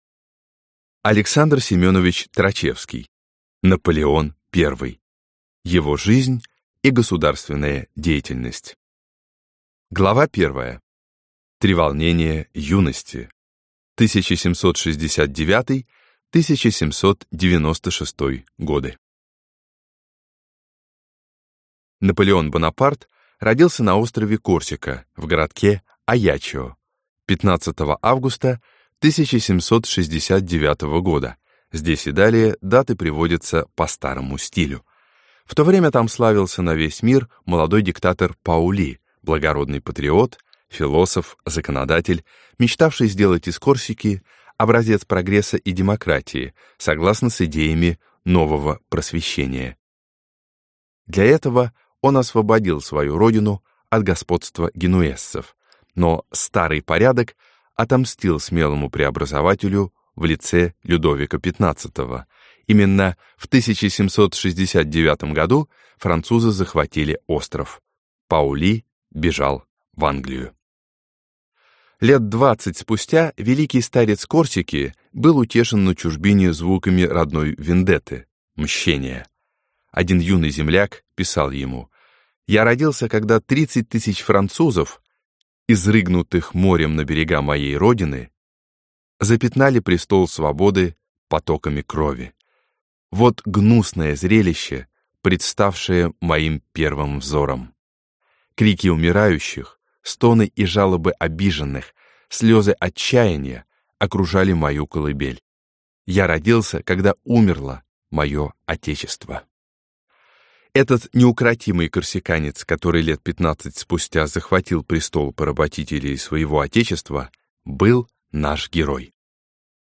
Аудиокнига Наполеон I. Его жизнь и государственная деятельность | Библиотека аудиокниг